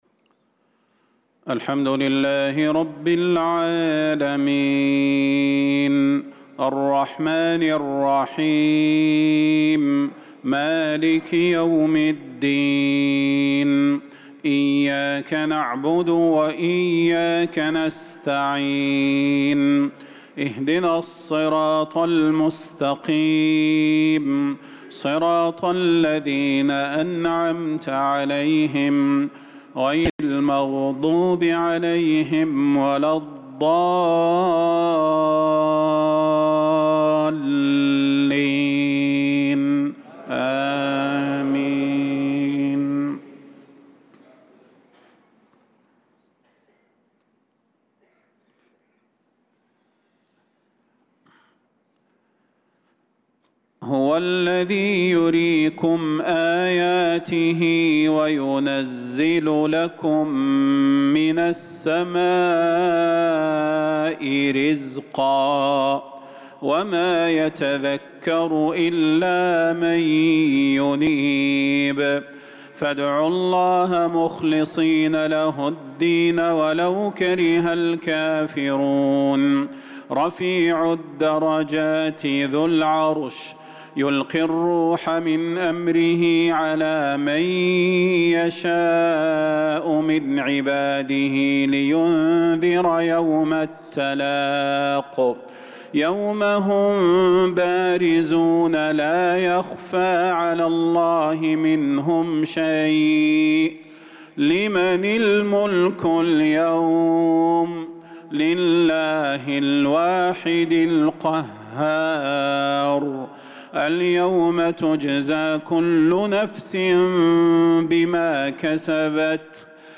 صلاة المغرب للقارئ صلاح البدير 18 شوال 1445 هـ
تِلَاوَات الْحَرَمَيْن .